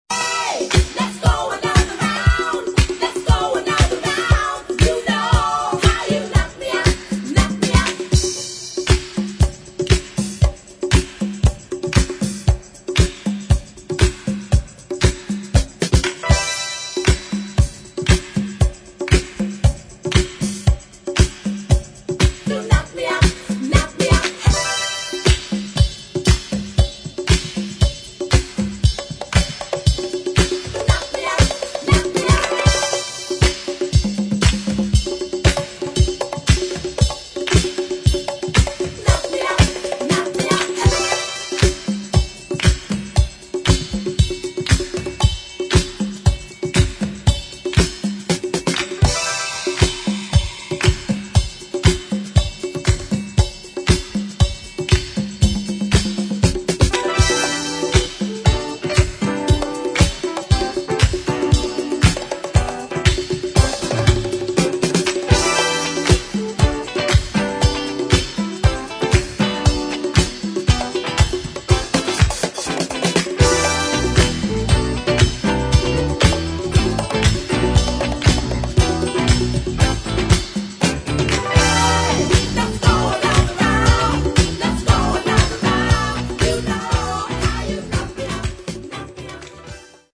[ DEEP HOUSE | DISCO ]